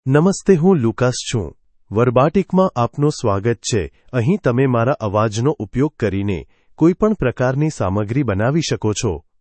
LucasMale Gujarati AI voice
Lucas is a male AI voice for Gujarati (India).
Voice sample
Male
Lucas delivers clear pronunciation with authentic India Gujarati intonation, making your content sound professionally produced.